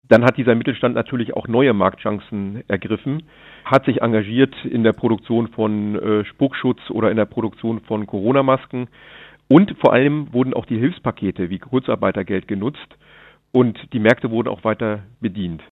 Landrat Martin Sommer